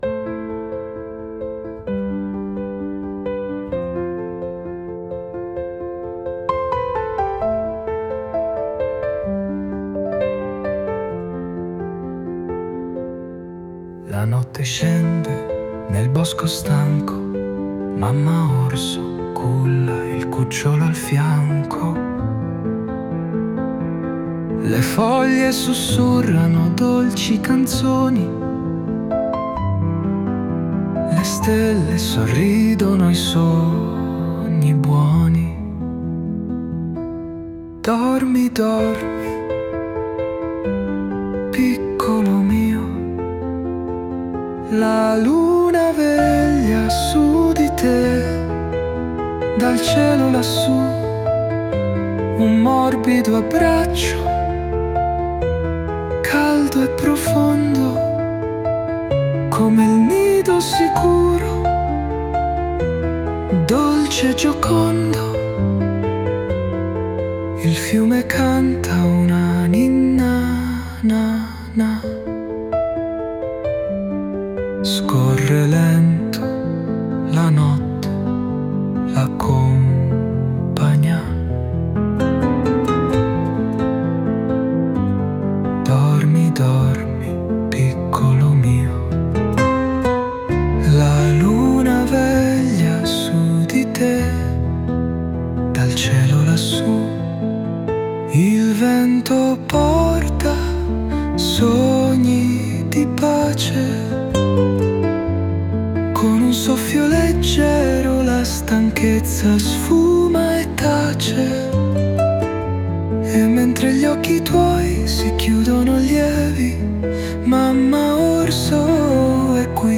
Ninna-Nanna-mamma-orso.mp3